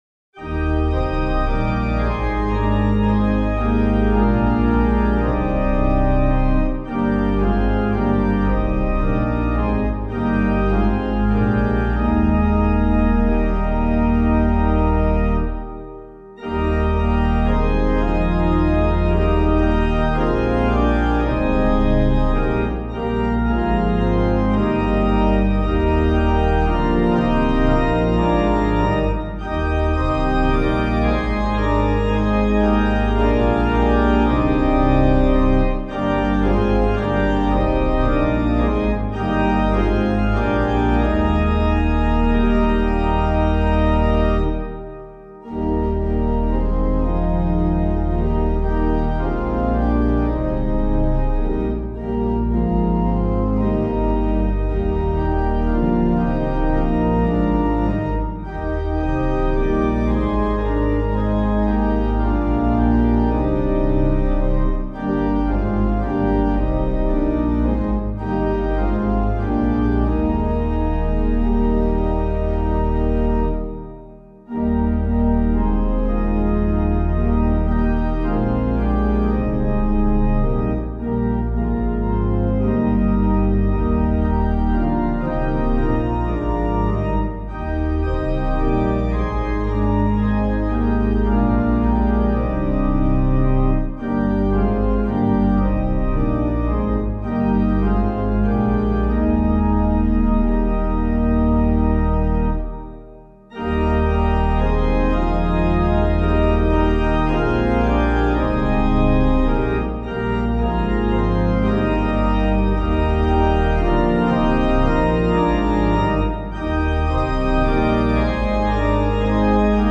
Hymn suitable for Catholic liturgy
Hymnals: CWB1:842 Downloads: accompaniment • chords • melody • organ • piano SUANTRAI Composer: Irish traditional melody; harmonized by Thomas Henry Weaving, 1881-1966, © Cumann Gaelach na hEaglaise.